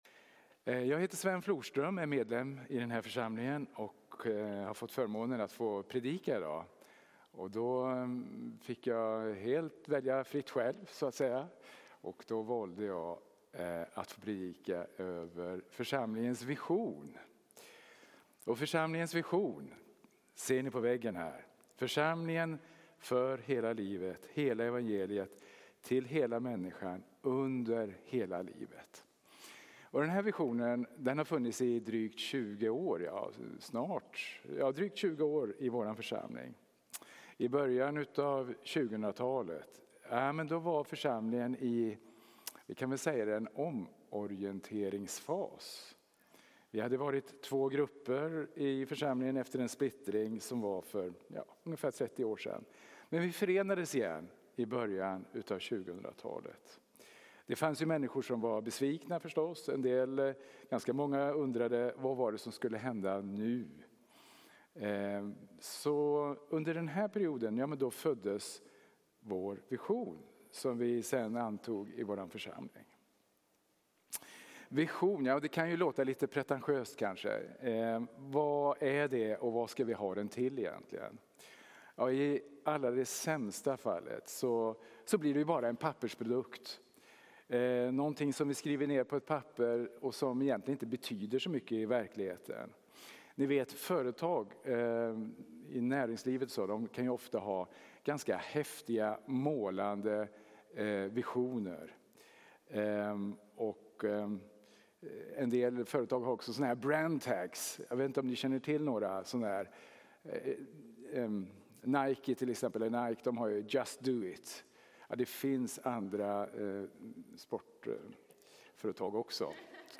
Undervisning från Trollhättan Pingst.